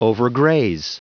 Prononciation du mot overgraze en anglais (fichier audio)
overgraze.wav